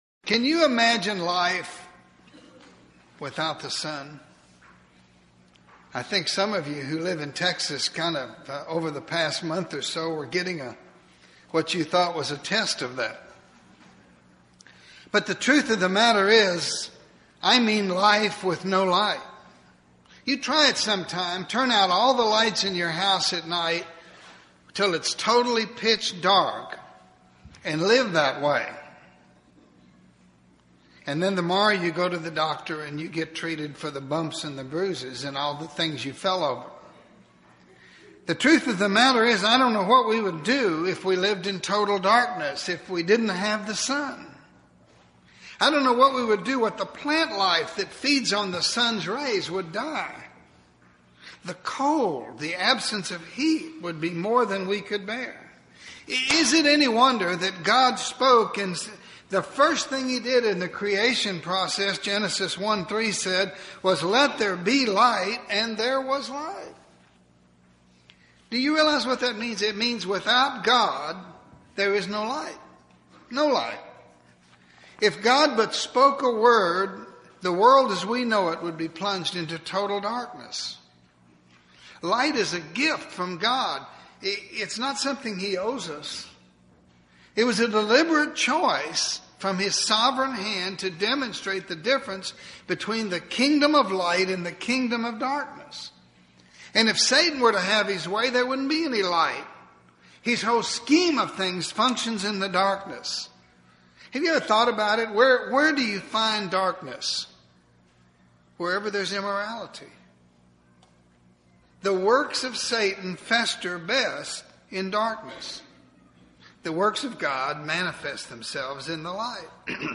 It is time to make the spiritual switch in this lesson as we focus on the sun.